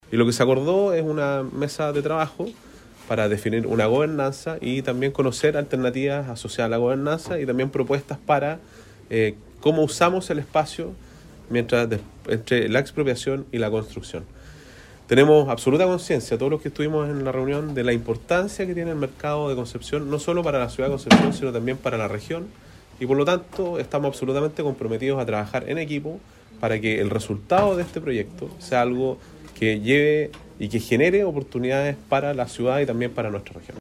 El gobernador regional, Sergio Giacaman, agregó que “los recursos están disponibles. Nosotros tenemos hoy la voluntad que este proyecto avance, pero lo importante es saber que pasará con la expropiación y la construcción del proyecto”.
MercadoCCP-2-Gobernador.mp3